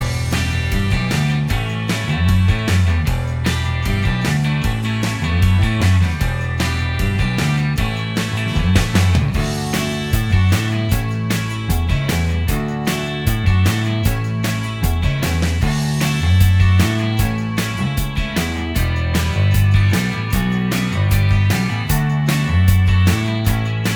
Minus Lead Guitar Pop (1980s) 3:51 Buy £1.50